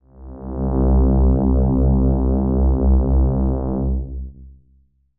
DX String C2.wav